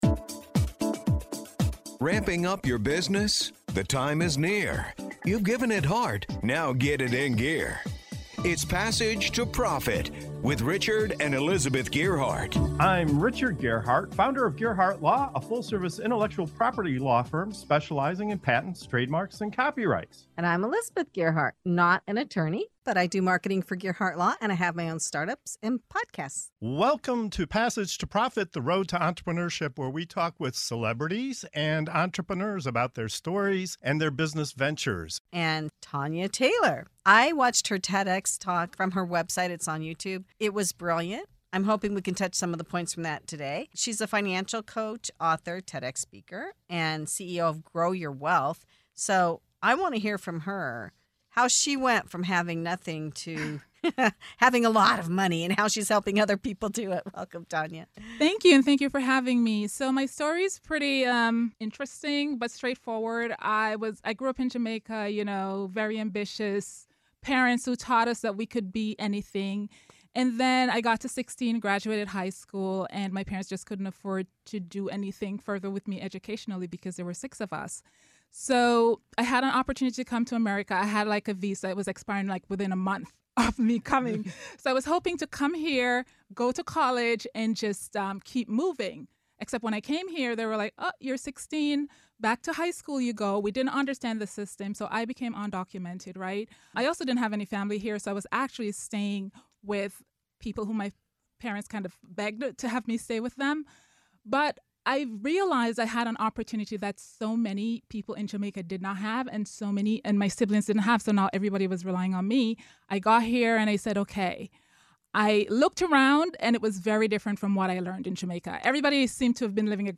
In this powerful interview